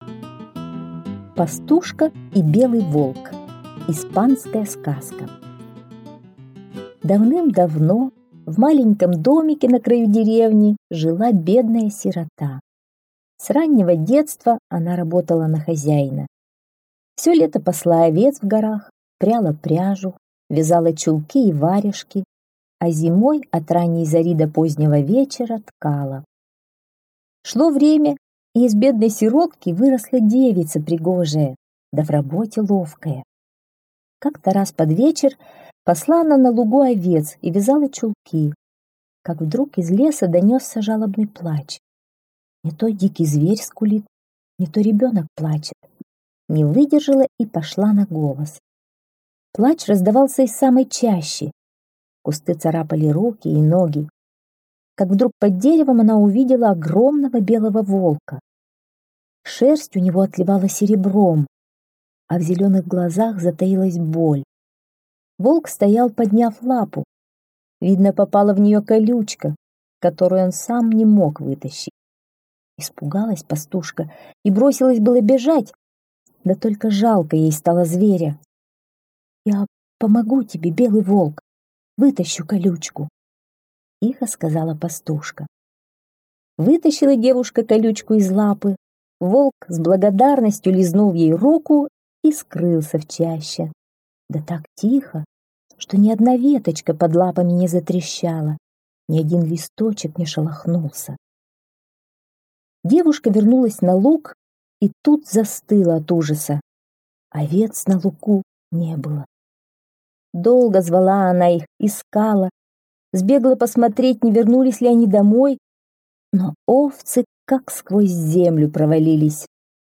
Пастушка и белый волк - испанская аудиосказка - слушать